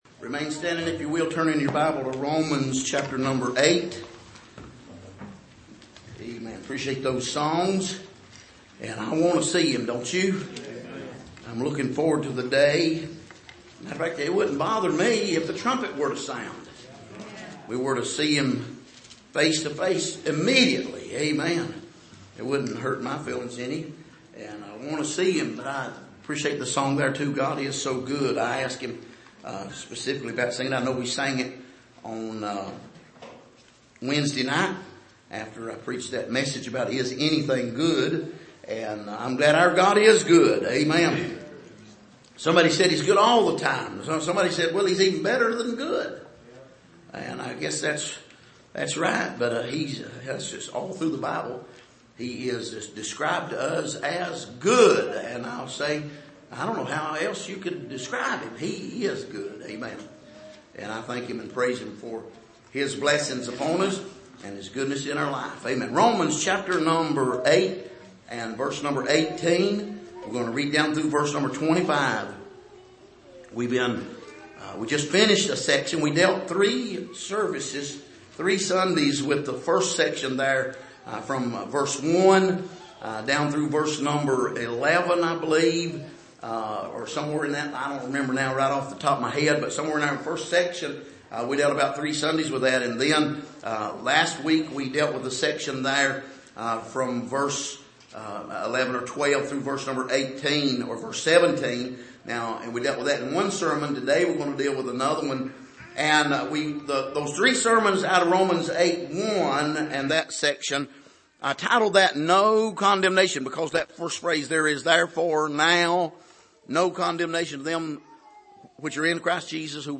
Passage: Romans 8:18-25 Service: Sunday Morning